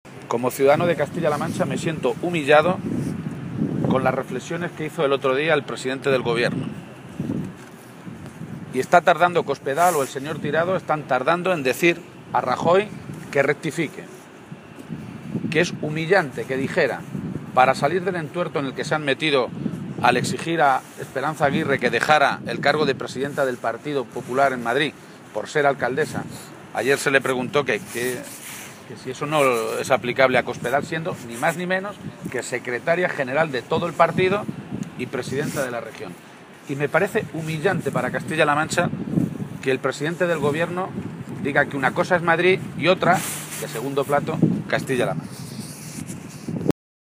García-Page se pronunciaba de esta manera esta mañana, en Cuenca, en una comparecencia ante los medios de comunicación, en la que aseguraba que lo más triste de este tipo de cosas (derrumbes de techos de hospitales y despidos como ejemplo del desmantelamiento de los servicios públicos esenciales) es que han ocurrido no solo después de que Cospedal prometiera que no iba a traspasar las líneas rojas de sus recortes en la Sanidad o la Educación, sino “después de que ver cómo vamos a terminar la legislatura con 6.000 millones de euros más de deuda pública. Es decir, que con Cospedal hemos acumulado más deuda pública en tres años que en los treinta anteriores de la historia” de Castilla-La Mancha.